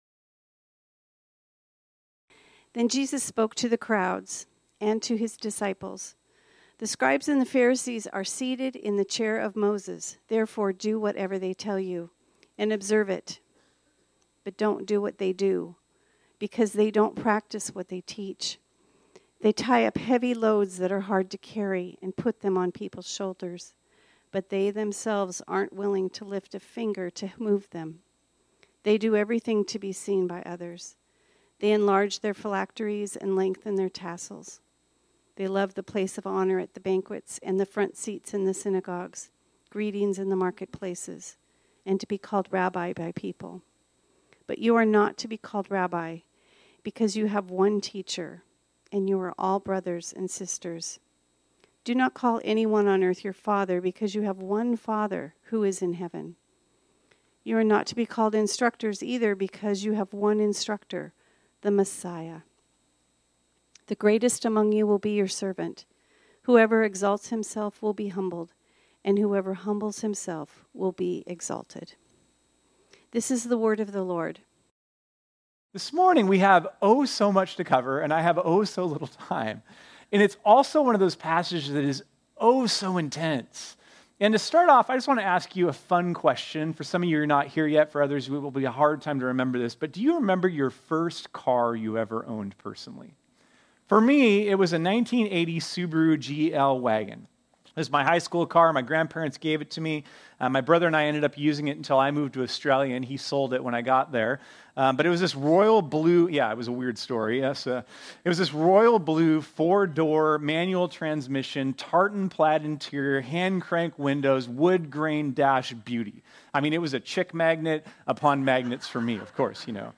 This sermon was originally preached on Sunday, October 13, 2024.